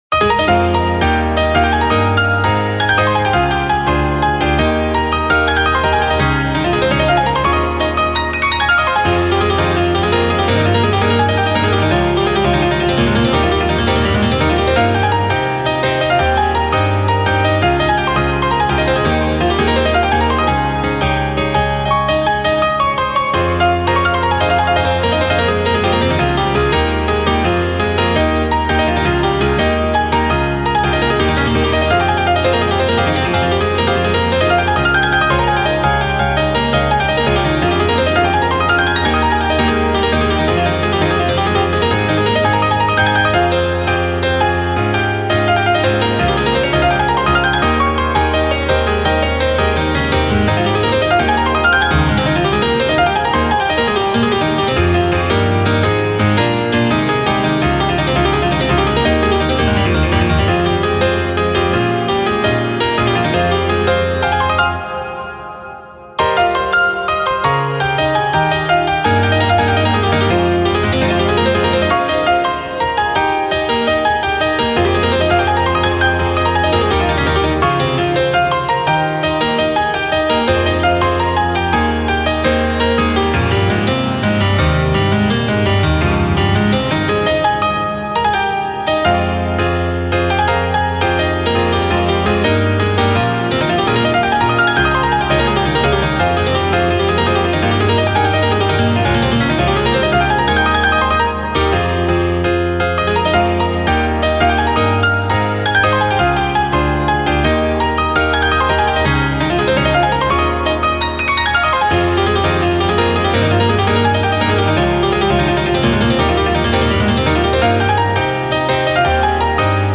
ジャンルピアノソロ
BPM１６８
使用楽器ピアノ
戦闘曲(Battle)